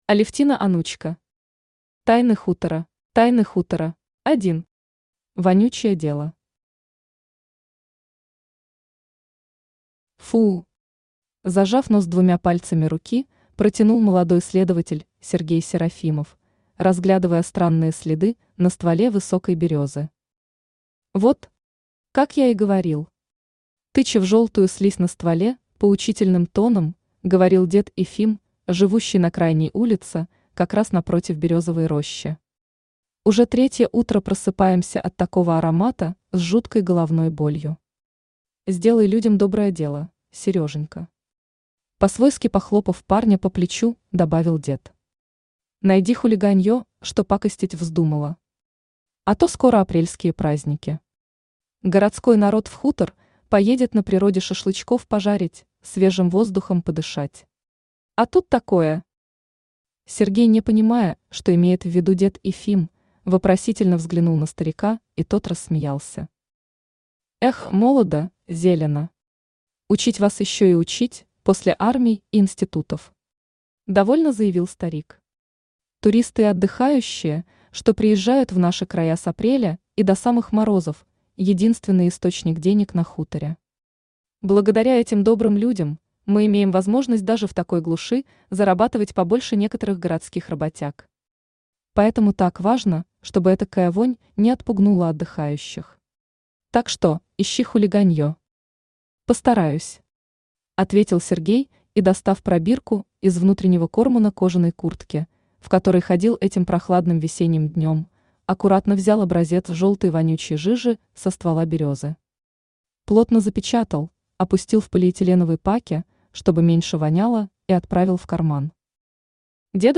Аудиокнига Тайны хутора | Библиотека аудиокниг
Aудиокнига Тайны хутора Автор Алевтина Александровна Онучка Читает аудиокнигу Авточтец ЛитРес.